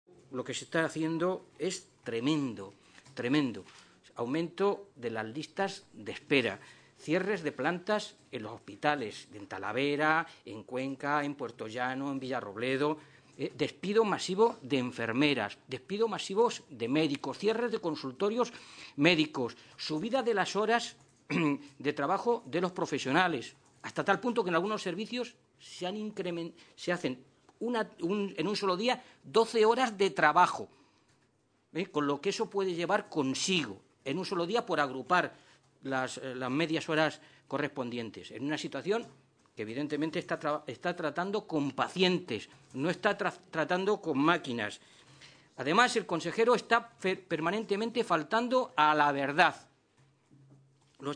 Guijarro y Mora a la entrada de las Cortes regionales